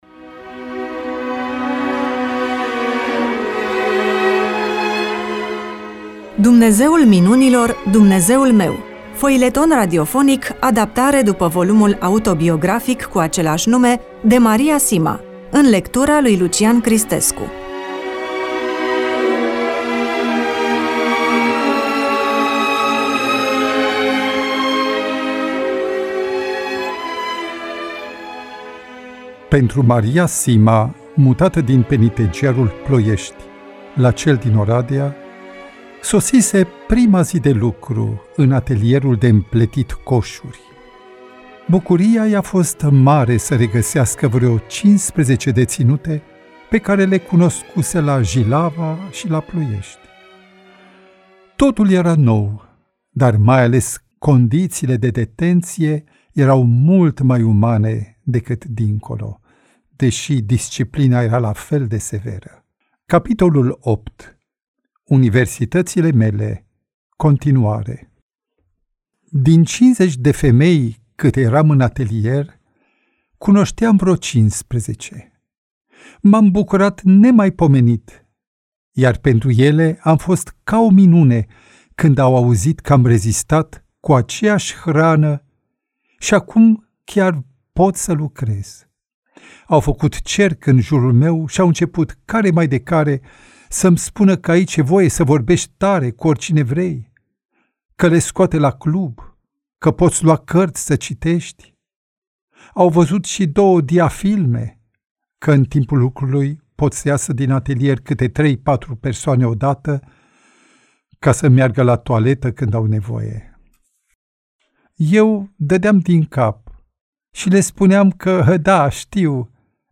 EMISIUNEA: Roman foileton DATA INREGISTRARII: 20.02.2026 VIZUALIZARI: 31